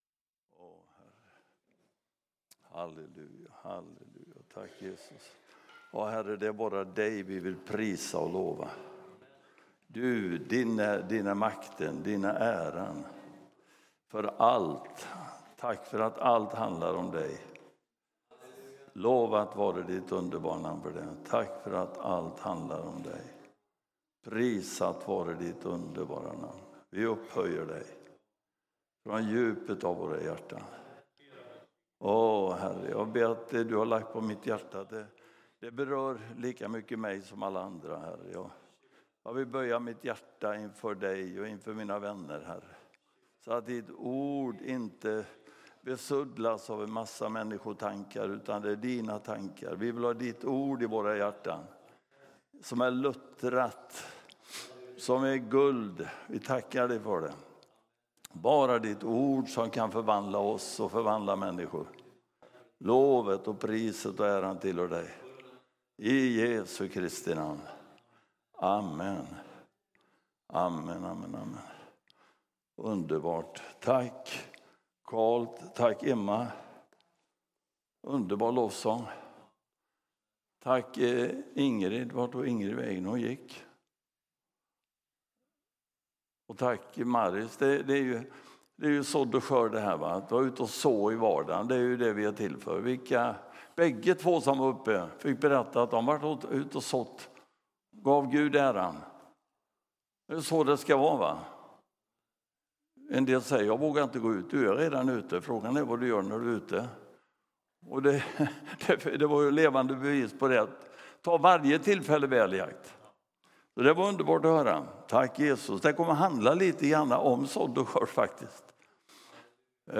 Pingstkyrkan Eksjö söndag 25 januari 2026